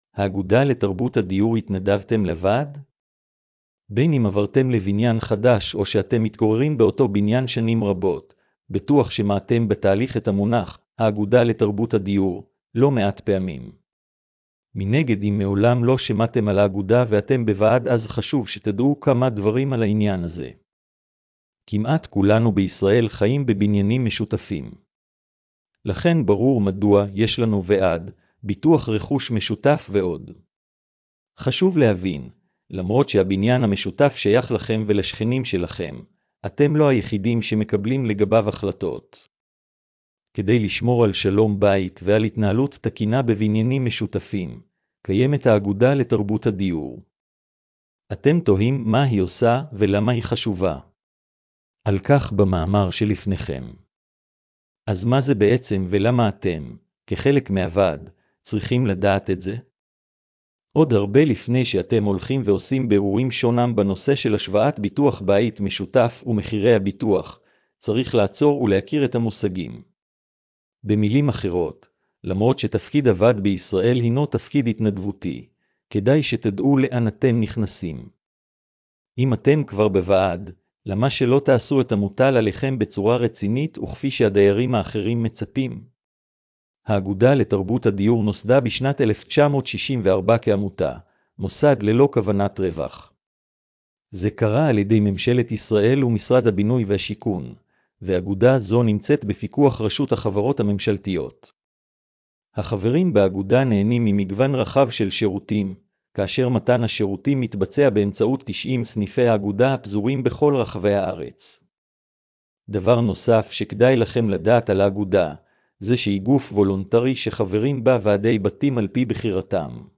השמעת המאמר לכבדי ראייה